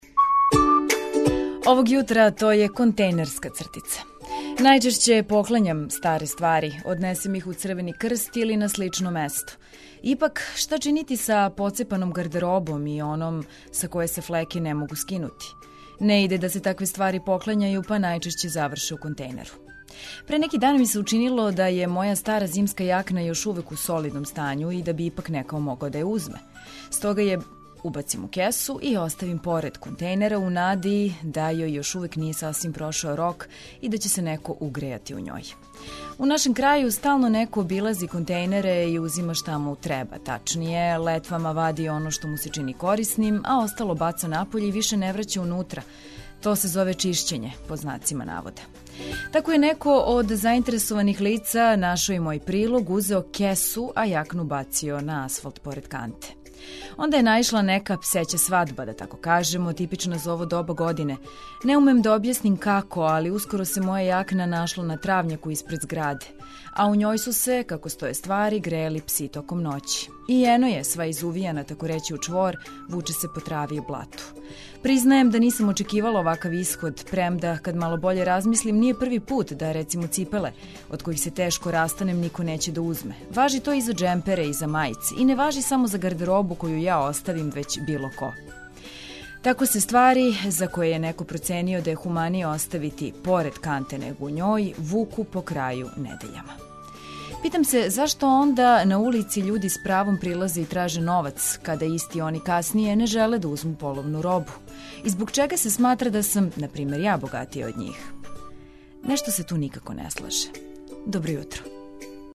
Разбуђивање је лепше уз радио!